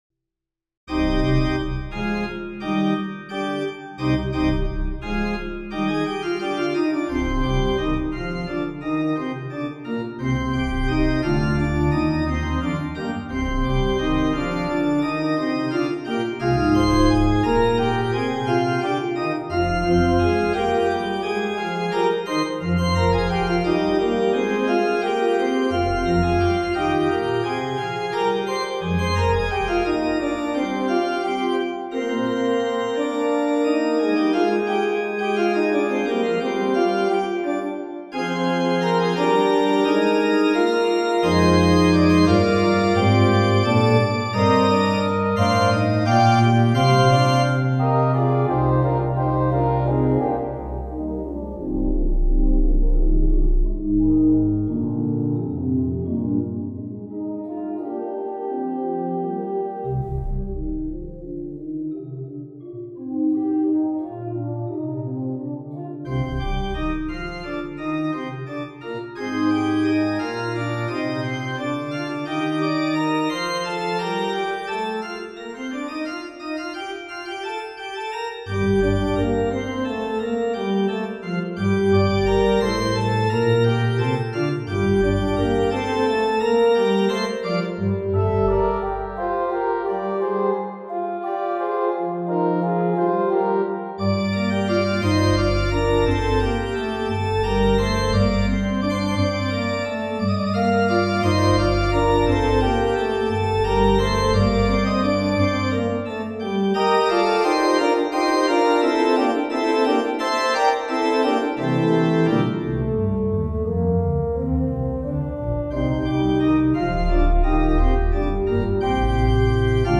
for organ
Here 9/8 is framed as 3+2+2+2, in dance-like andantino.